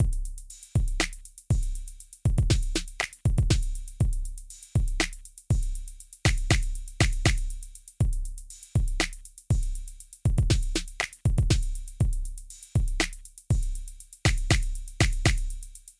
嘻哈节拍16条
描述：gular HipHop鼓16支
Tag: 120 bpm Hip Hop Loops Drum Loops 2.69 MB wav Key : Unknown